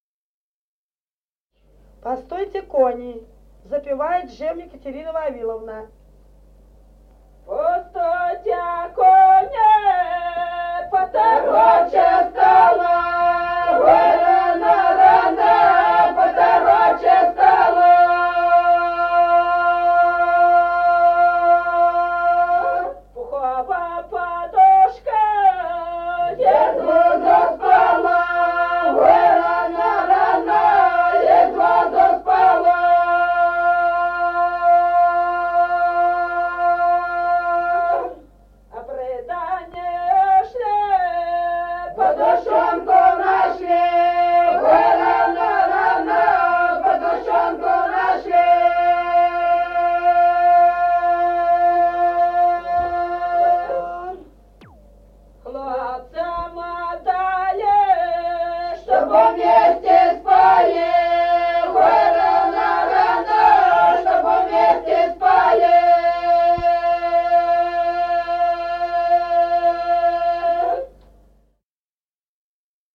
Народные песни Стародубского района «Постойте, кони», свадебная.
1953 г., с. Остроглядово.